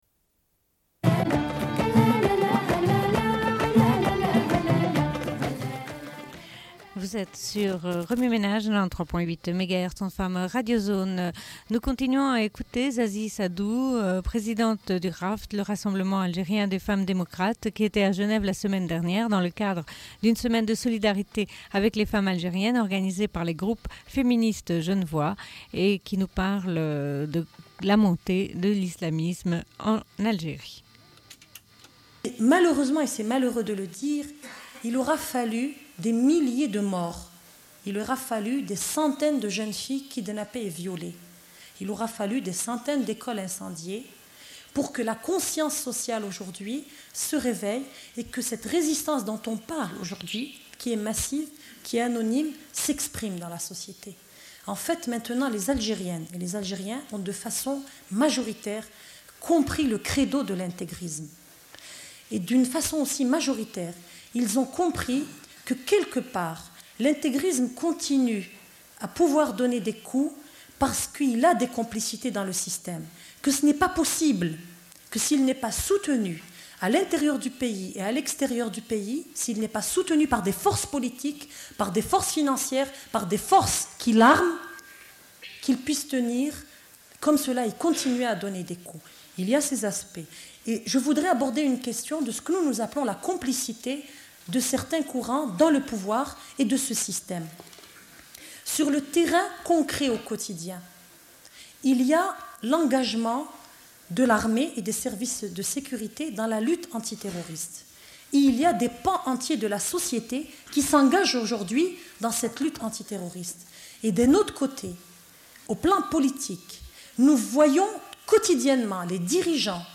Une cassette audio, face B29:06
Suite de l'émission : diffusion d'un séminaire du 15 juin 1995 organisé par EFI, le Collectif du 14 juin et d'autres groupes féministes genevois à l'occasion d'une semaine de solidarité avec les femmes algériennes autour du 14 juin.